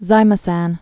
(zīmə-săn)